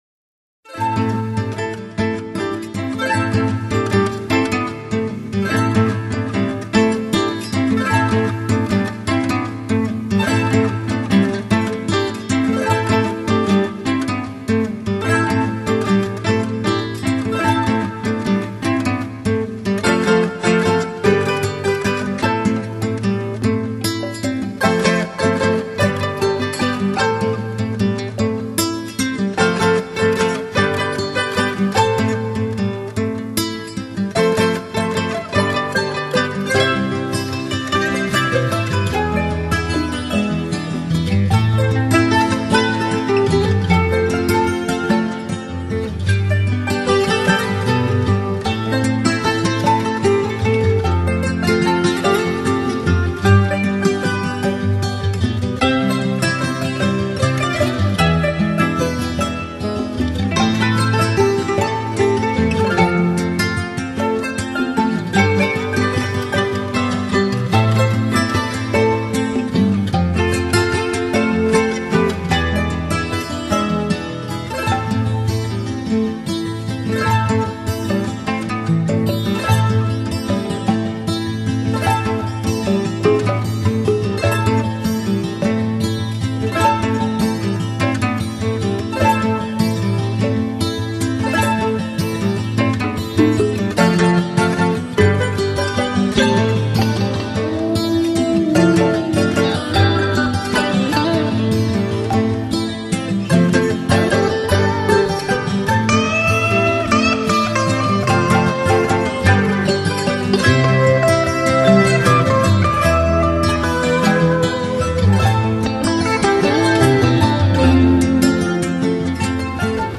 风格：New Flamenco
本片结合吉普赛人流浪的情结，加上东方的迷幻多情，使整张专辑营造出一幕幕幽远意象，如泣如诉地演奏出感人动听的旋律。
可惜只有64k品質--只有心領了